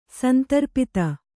♪ santarpita